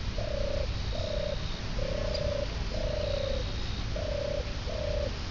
tubare (125 KB) "sussurrato" le distingue dalla Tortora dal Collare.
tortoraselvatica.wav